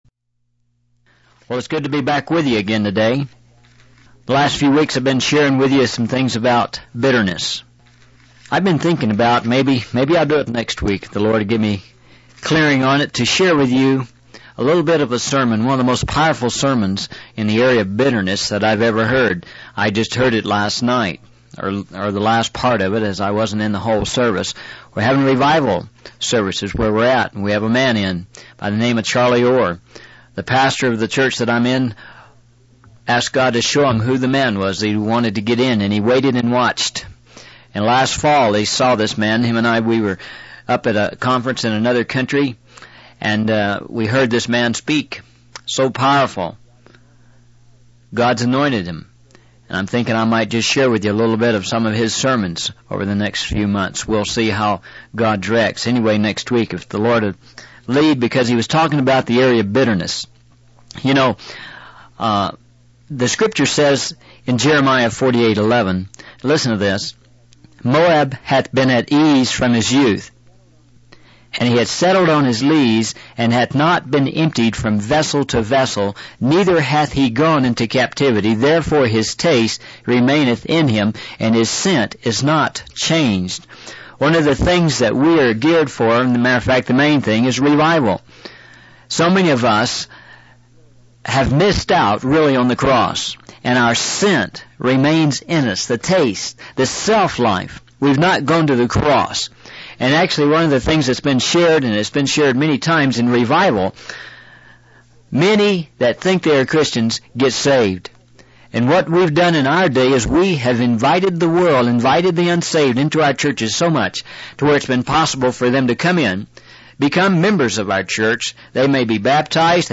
In this sermon, the preacher emphasizes the power of words and their impact on our lives. He explains that our words can either justify or condemn us. He also highlights God's grace and willingness to give us second chances when we fail.